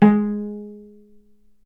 healing-soundscapes/Sound Banks/HSS_OP_Pack/Strings/cello/pizz/vc_pz-G#3-ff.AIF at f6aadab7241c7d7839cda3a5e6764c47edbe7bf2
vc_pz-G#3-ff.AIF